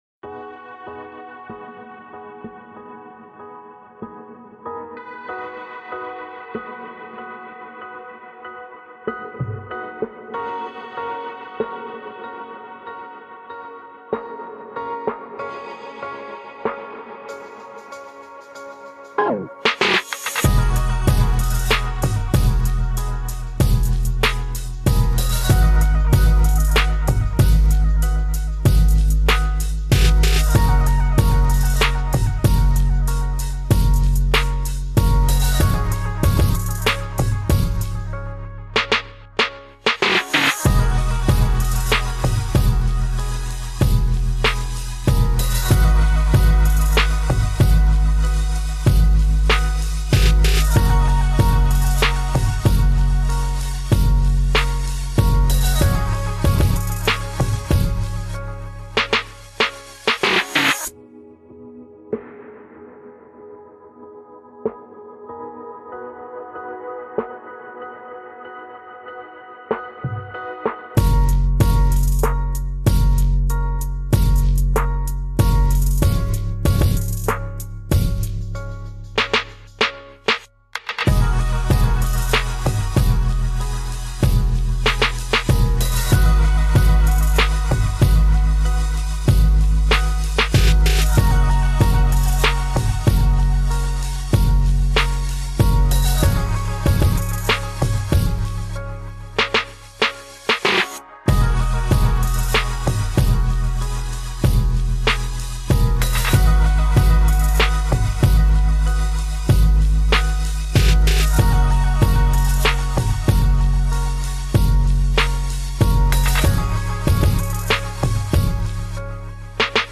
Pluie et Feu : Ambiance Focus